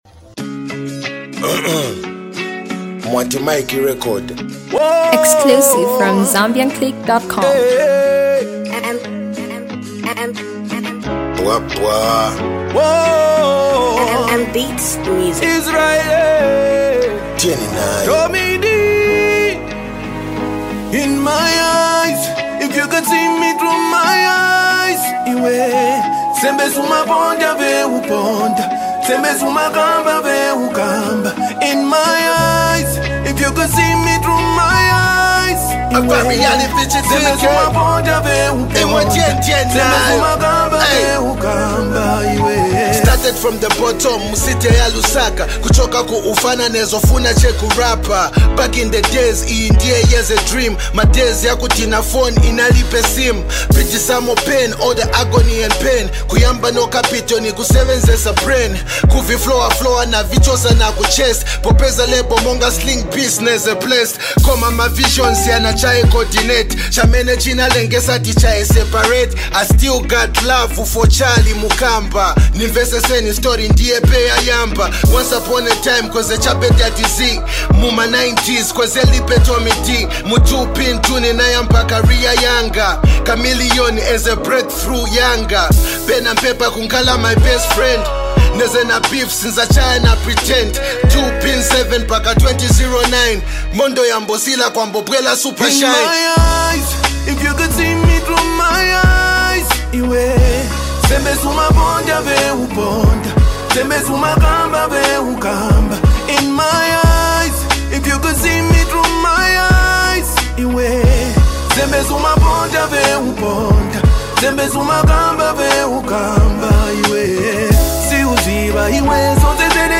Zambian hardcore hip hop